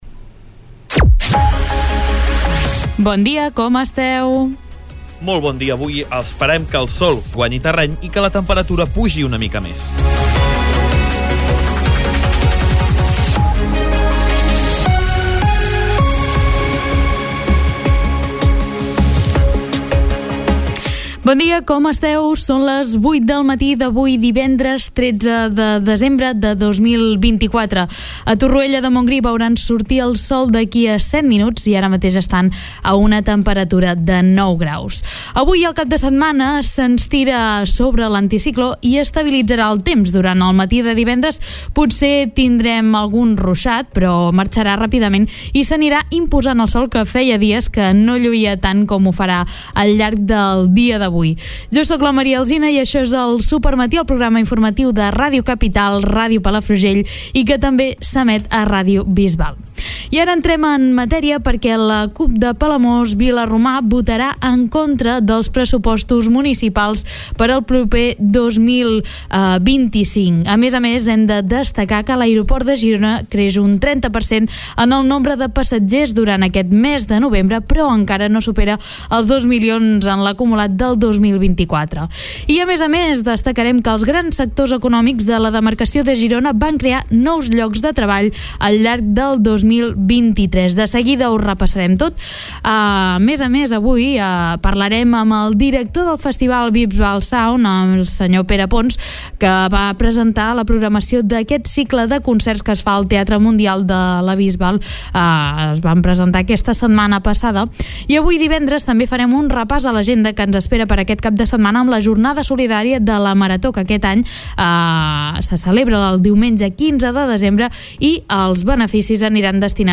Recupera el programa informatiu d'aquest dimarts